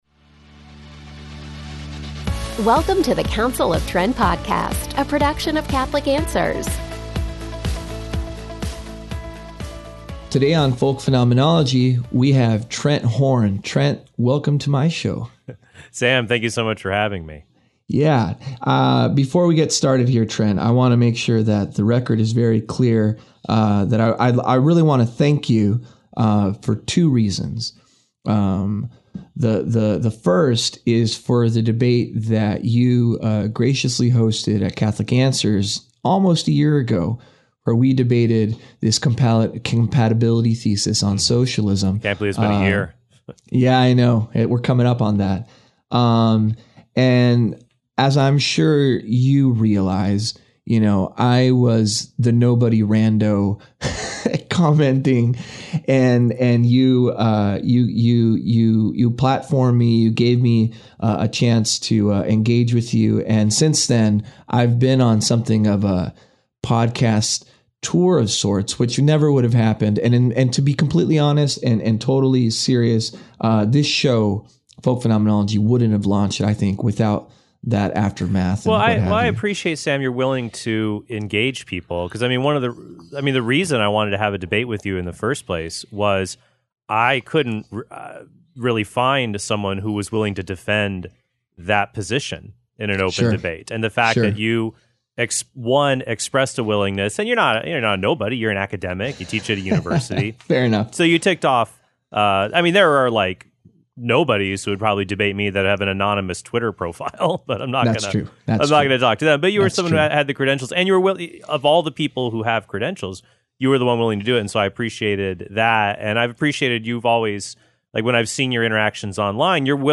DIALOGUE: Can a Catholic be a Capitalist?